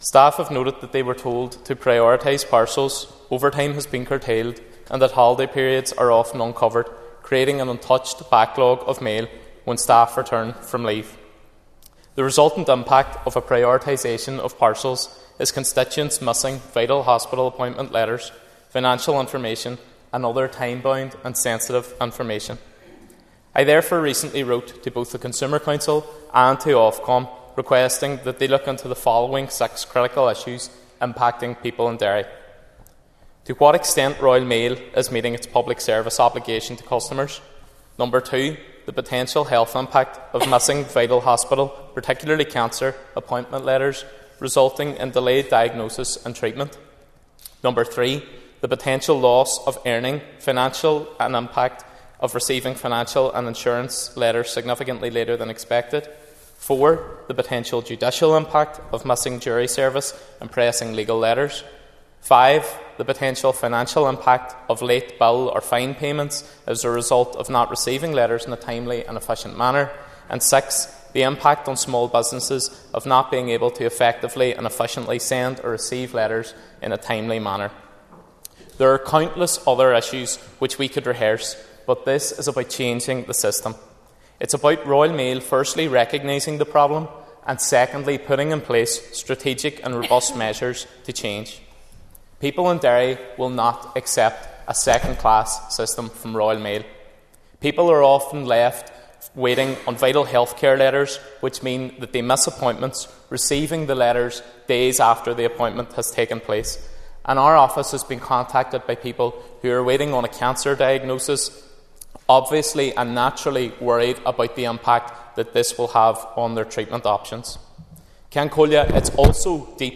He added that staff at Royal Mail are bearing the brunt of bad decisions made at management level: